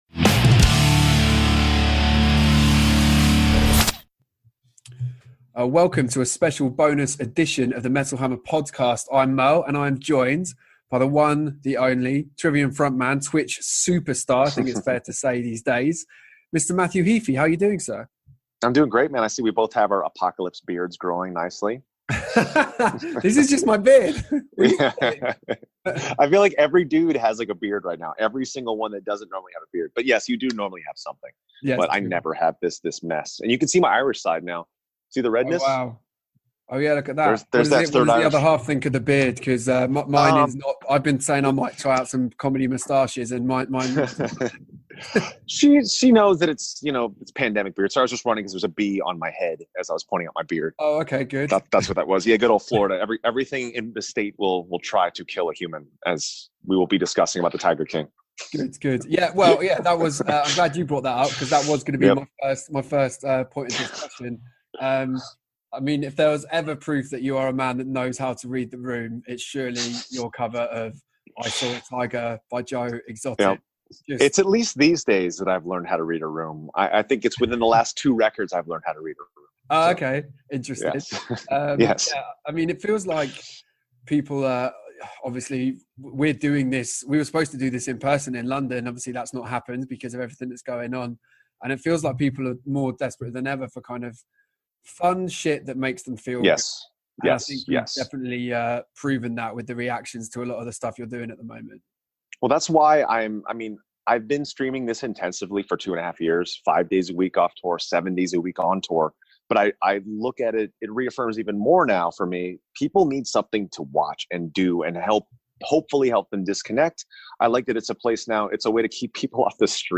In a special bonus podcast, Trivium frontman Matt Heafy talks